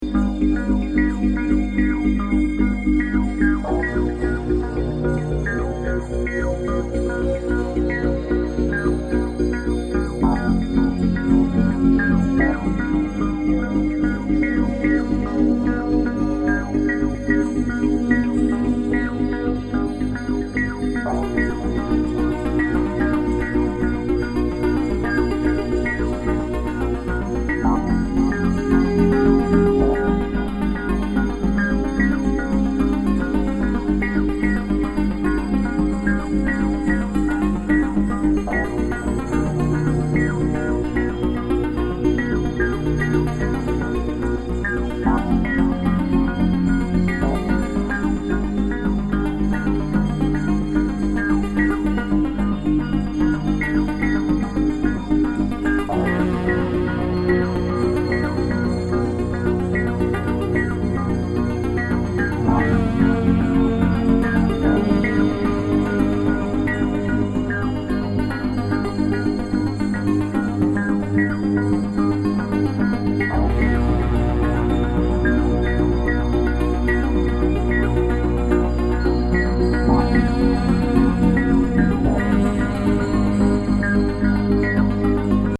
Powerful cyclic robust techno.